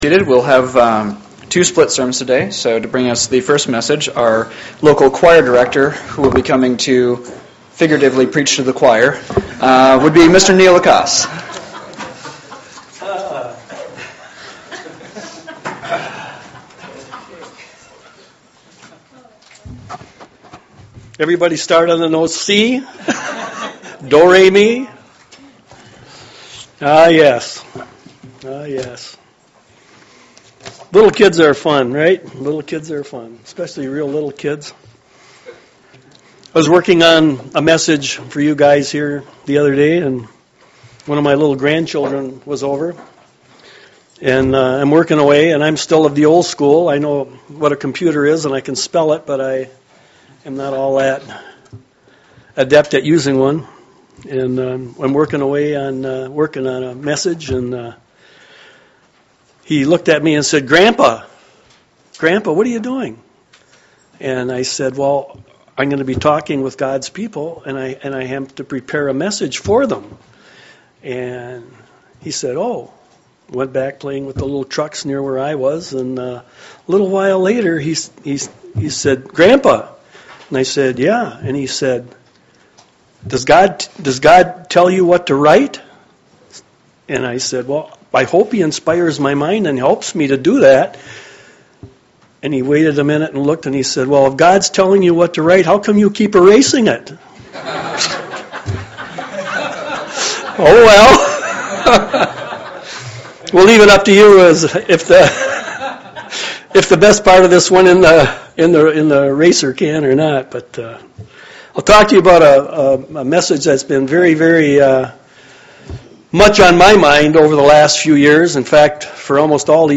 Given in Lansing, MI
UCG Sermon